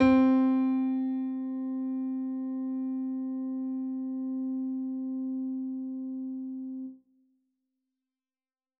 Wolf Piano.wav